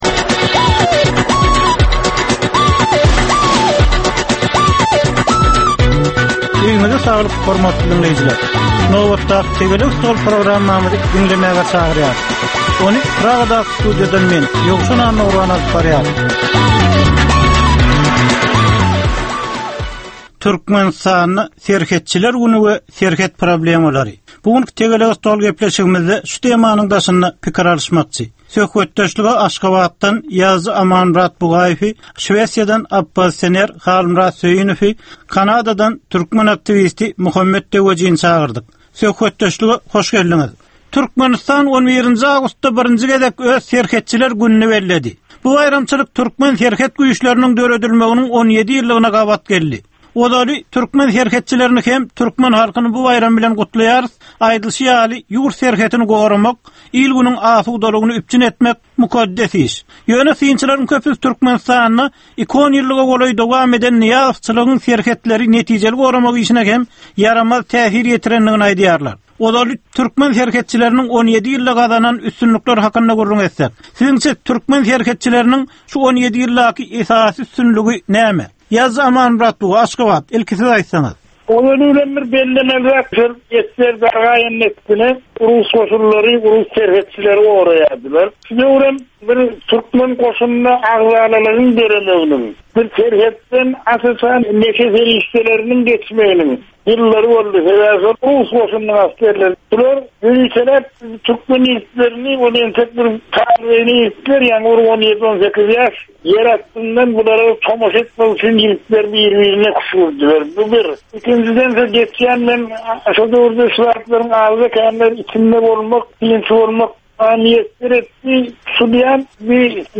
Jemgyýetçilik durmuşynda bolan ýa-da bolup duran soňky möhum wakalara ýa-da problemalara bagyşlanylyp taýyarlanylýan ýörite Tegelek stol diskussiýasy. 25 minutlyk bu gepleşhikde syýasatçylar, analitikler we synçylar anyk meseleler boýunça öz garaýyşlaryny we tekliplerini orta atýarlar.